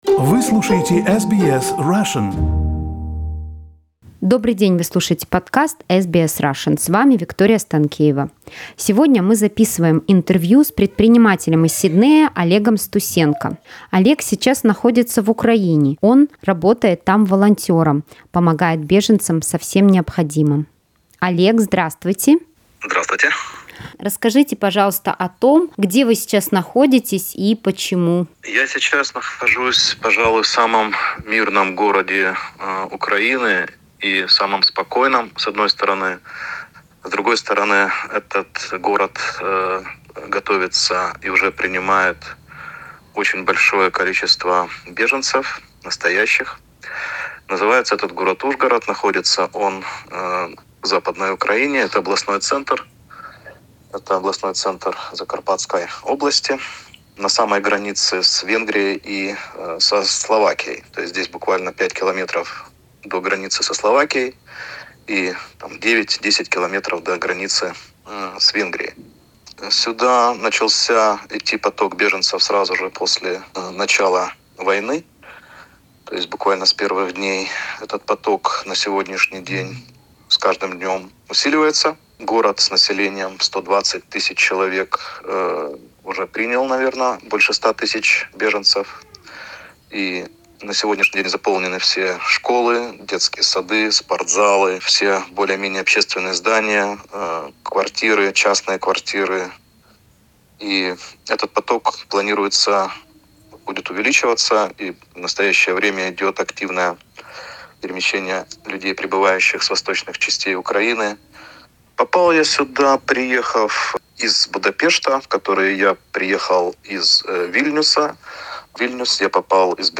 Интервью с предпринимателем из Сиднея, который после начала войны поехал в Россию, выходил там на одиночные пикеты, а затем отправился в украинский приграничный город Ужгород помогать беженцам.